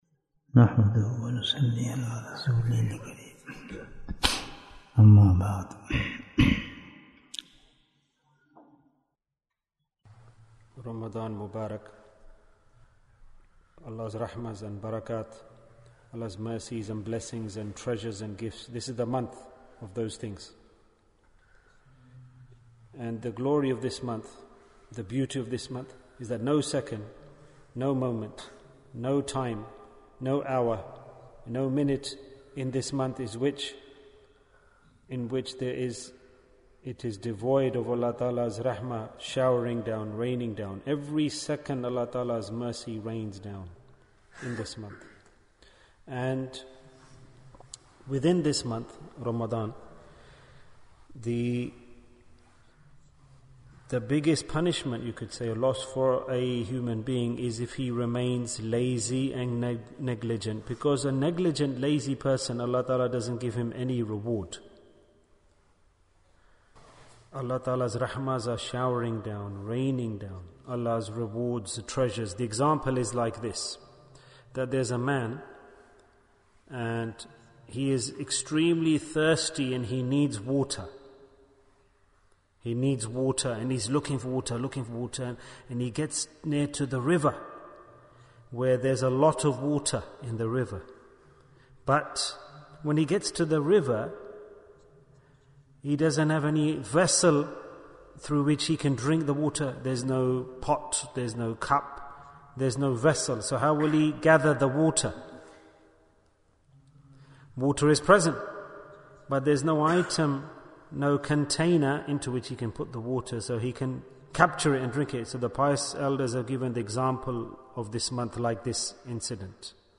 Bayan, 28 minutes25th March, 2023